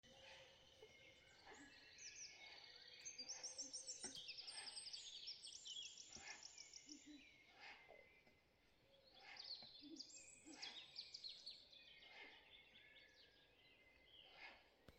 Birds -> Owls ->
Ural Owl, Strix uralensis
StatusPair observed in suitable nesting habitat in breeding season
NotesDivas urālpūces novērotas savstarpēji sadziedoties, ko ar austiņām var dzirdēt audio fragmentā. viena novērota arī medījot.